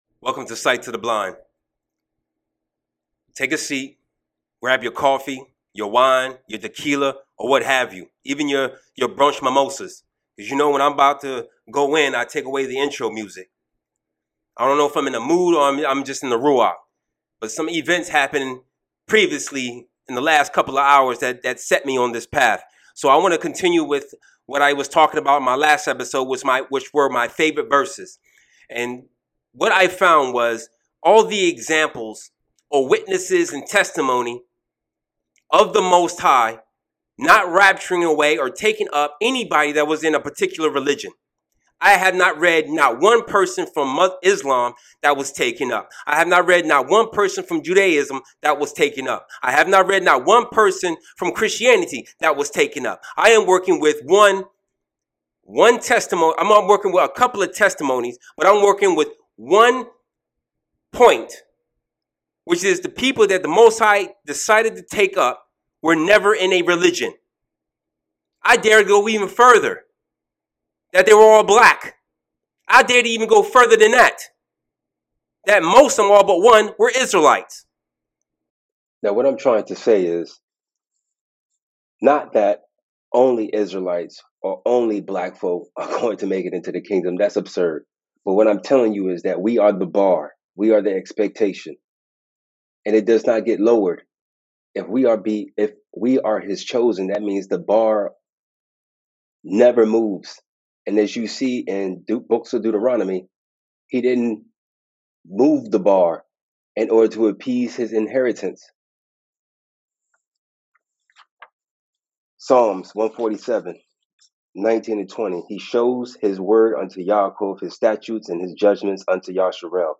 My apologies for the audio, technical difficulties.